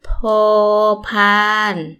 – poor – paan